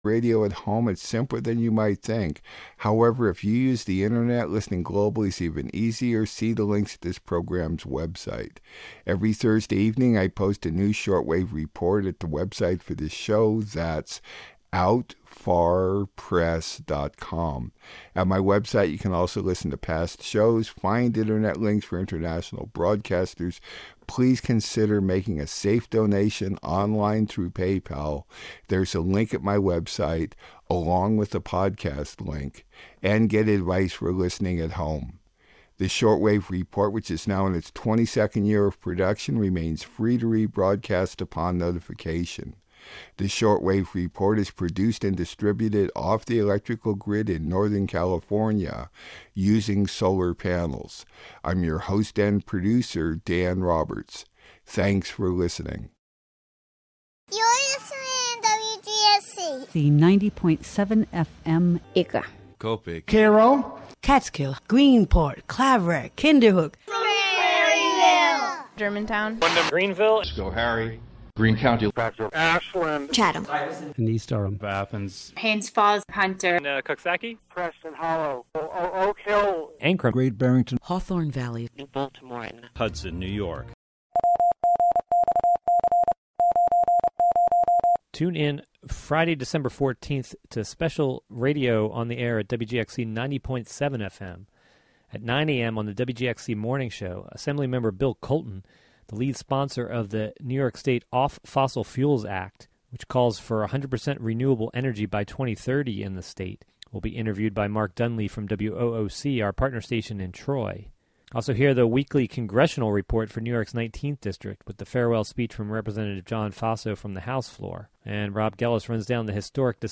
"All Together Now!" is a daily news show covering...